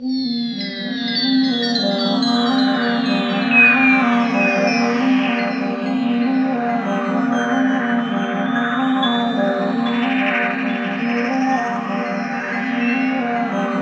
SYN JD80006L.wav